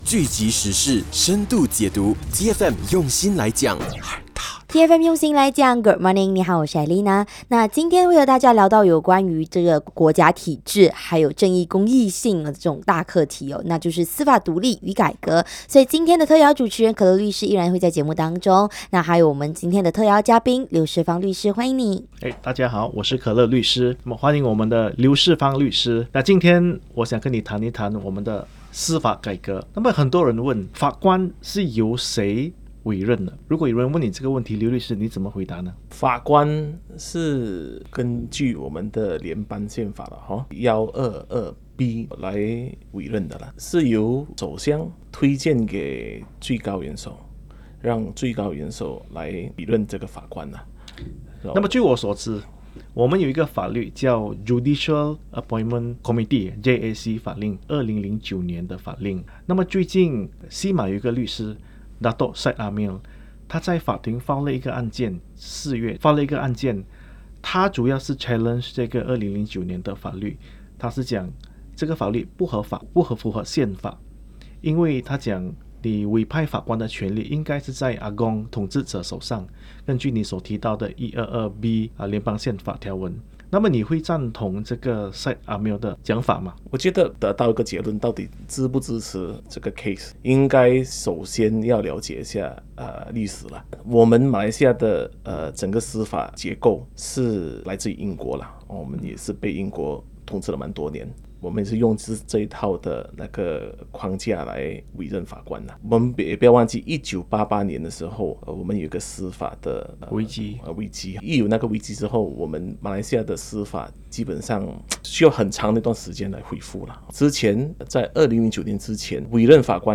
《好好再一起》电影访问